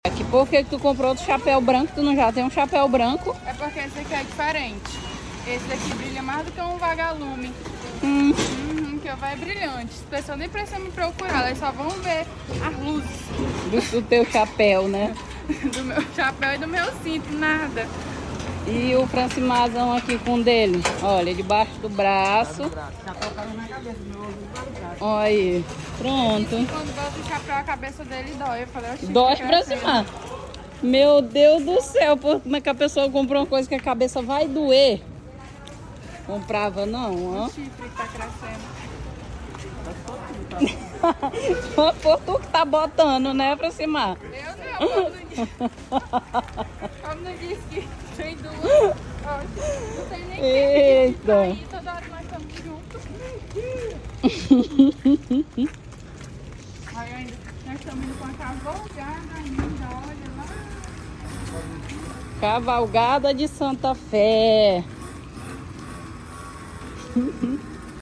tenho uma amiga aqui em São paulo tem o mesmo sotaque dela , adoro esse sotaque.